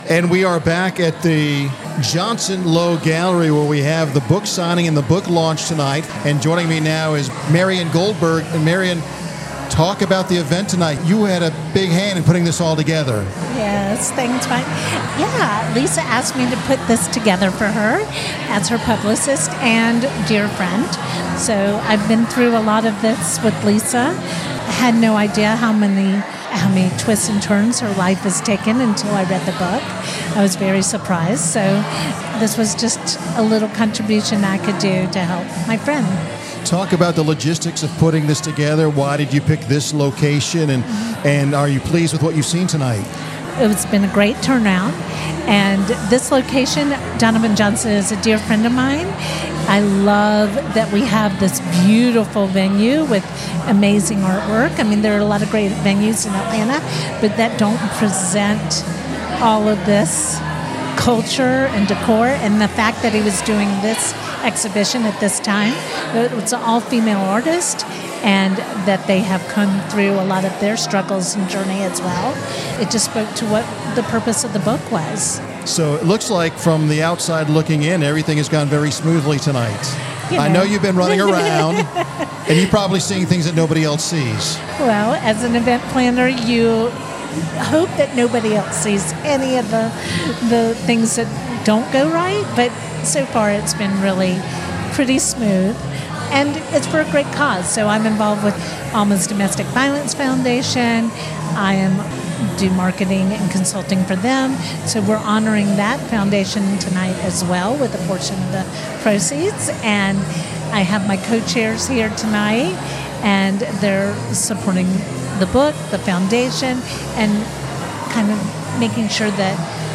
Business RadioX was on site to interview the author and distinguished guests attending the event, courtesy of Riptide, LLC.